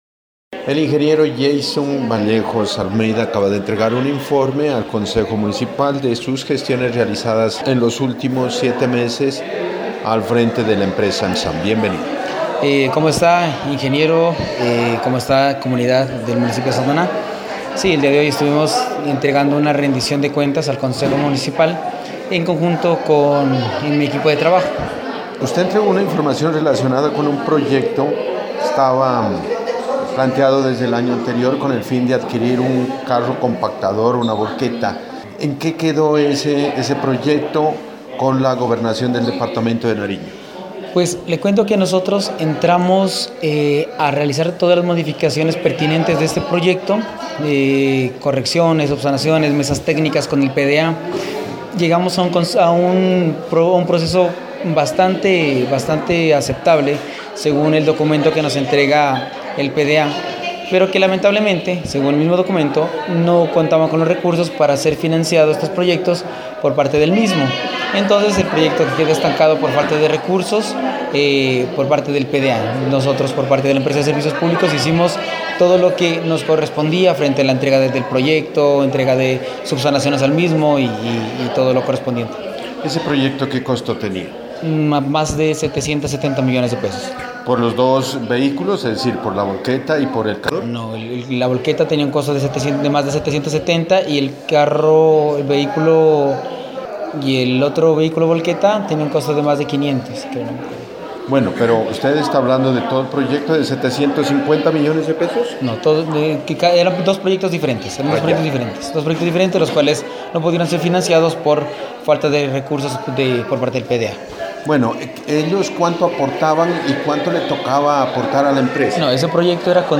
rindió cuentas este martes en la sesión del Concejo Municipal.
En la primera parte el funcionario rindió cuentas antes los integrantes de la Corporación Municipal y posteriormente respondió preguntas de los concejales y de las personas que estuvieron presentes.